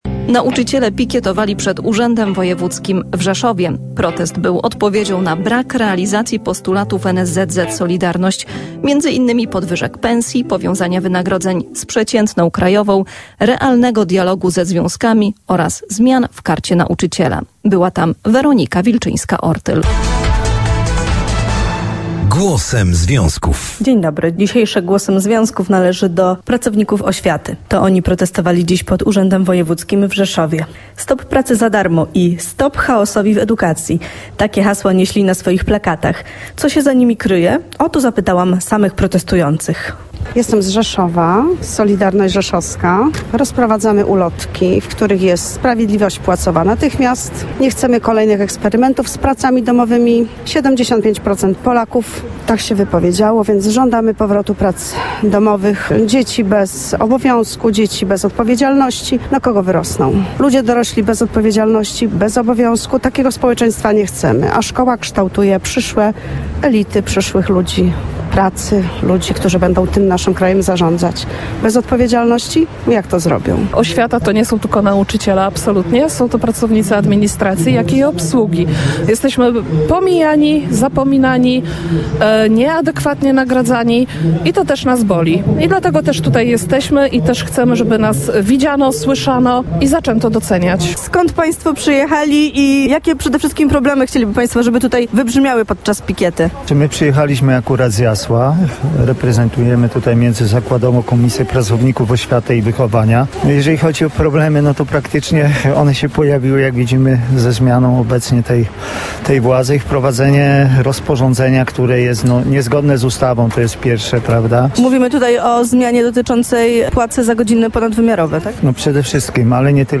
Audycja w Radiu Rzeszów >>>